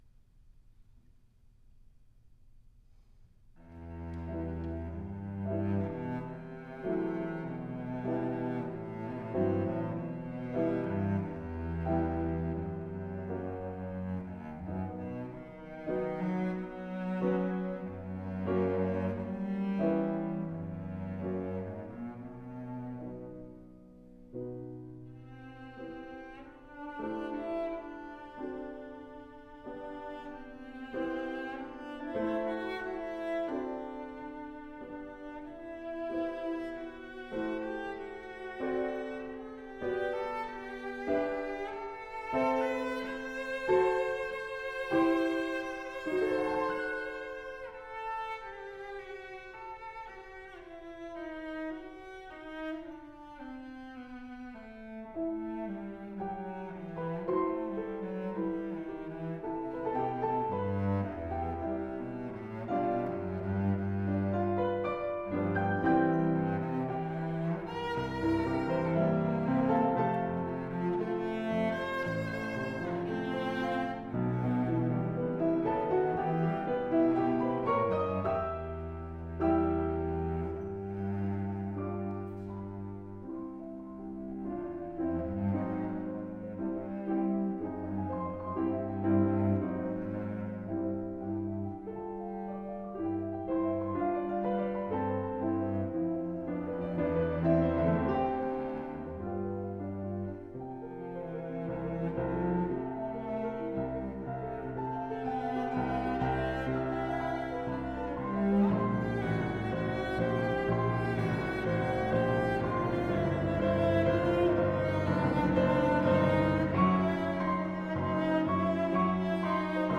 Playing the Cello
brahms-cello-sonata-1.m4a